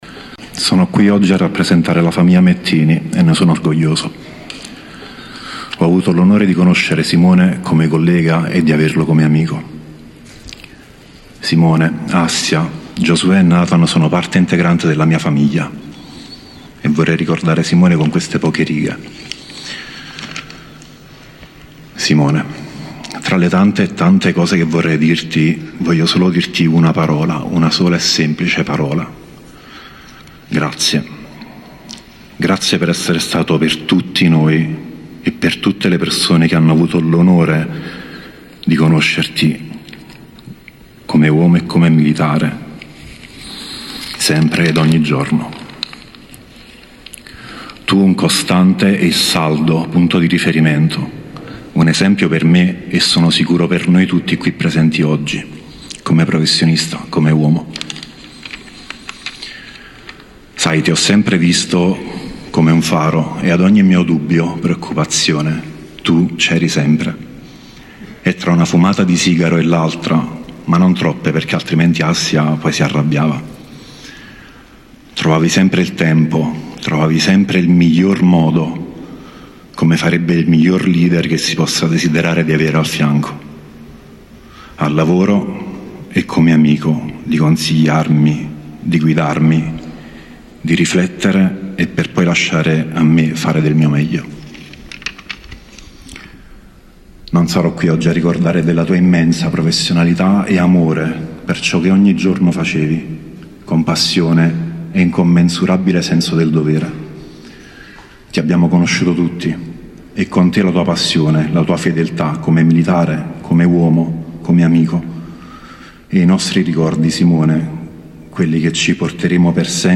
Durante le celebrazioni, nell’hangar del Comani